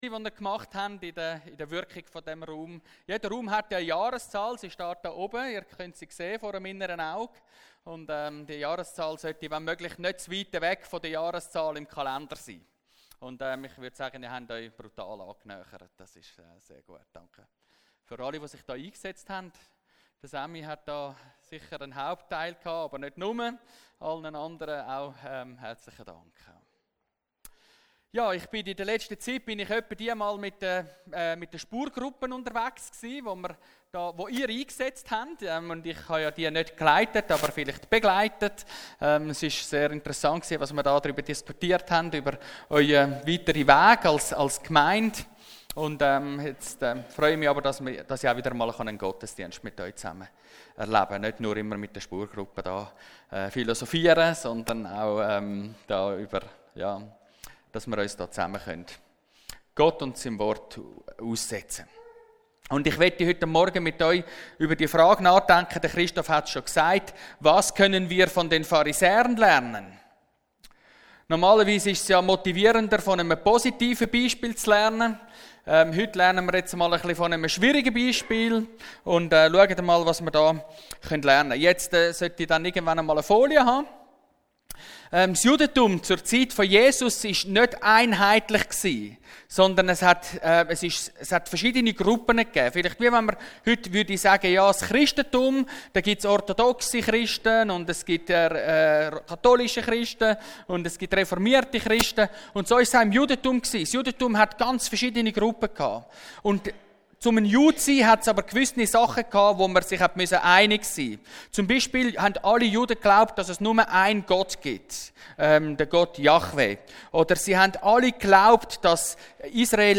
Predigt vom 22.09.2019